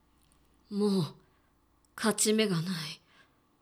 ボイス
中性